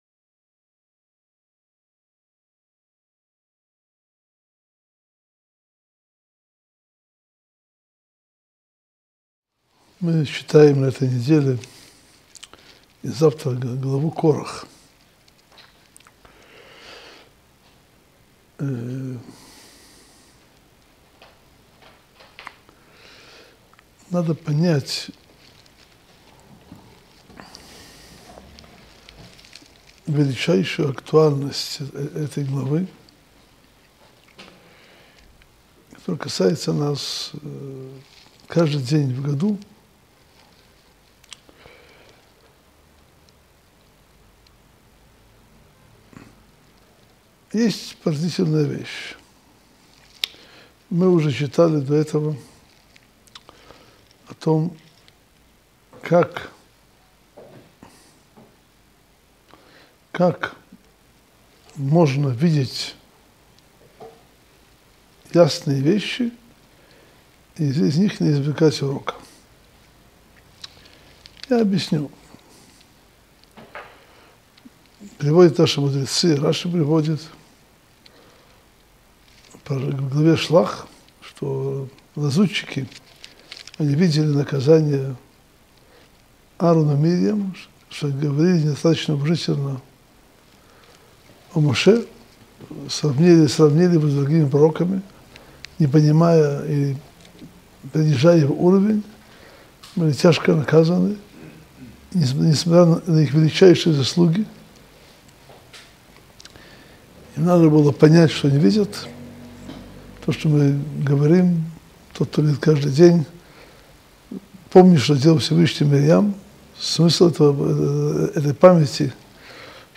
Уроки главы Хукат